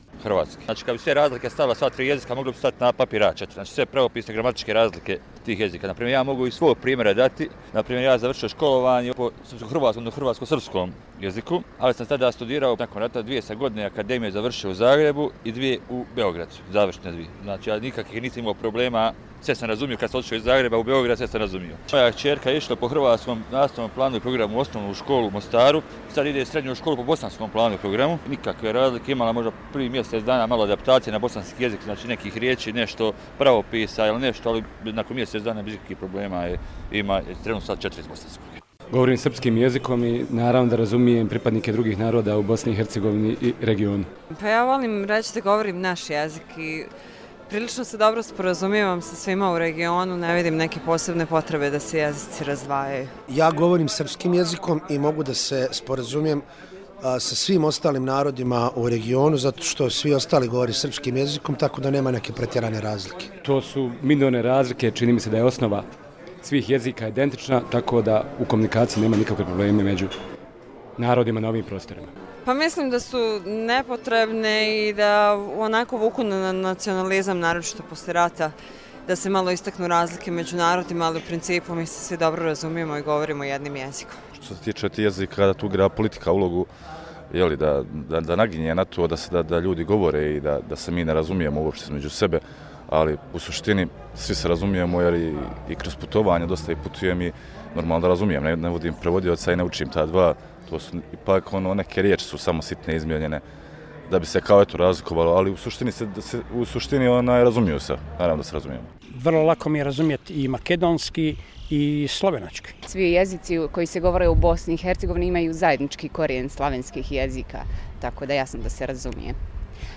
Anketa: Dan maternjeg jezika